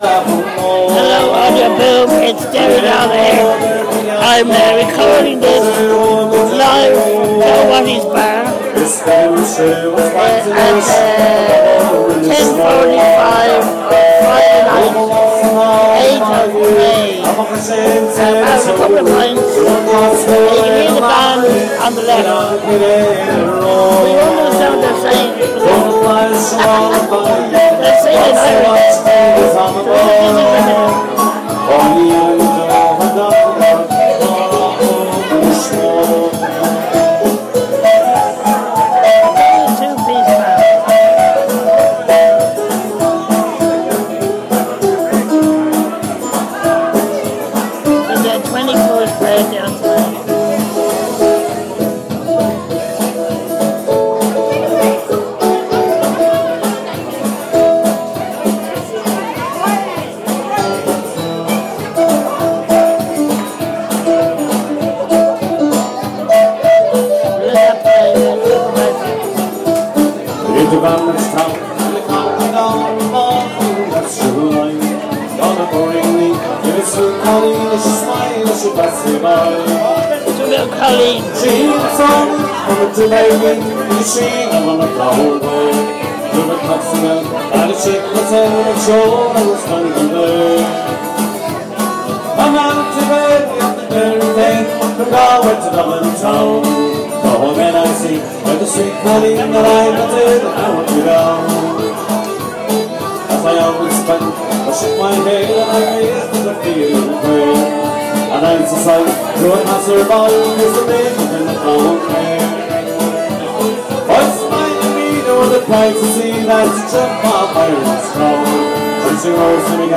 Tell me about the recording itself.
pub band